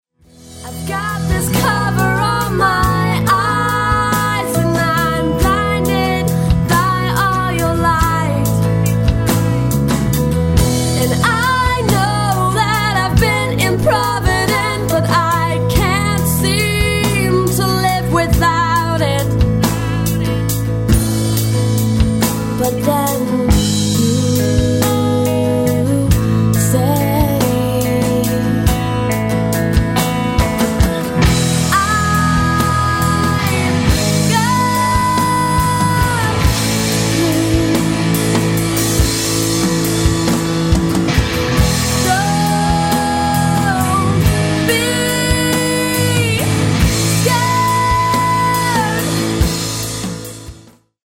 Rock - Acoustic